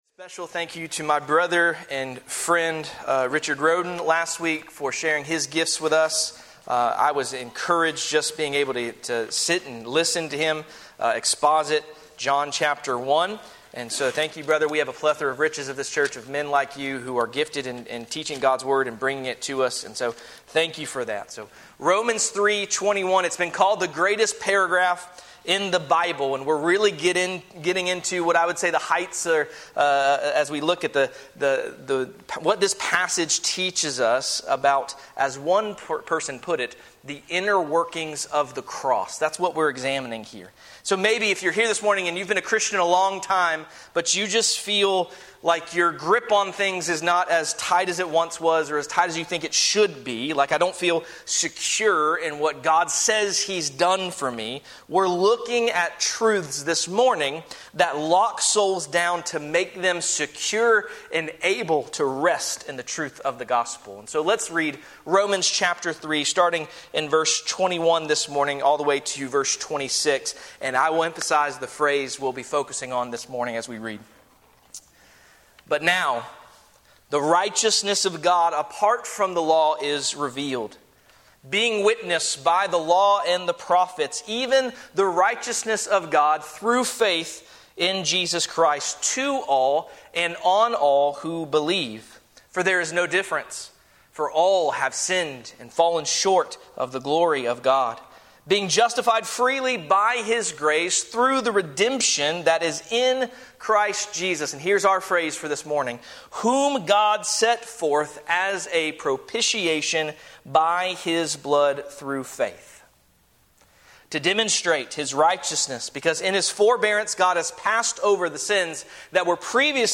Sermons | First Baptist Church of Gray Gables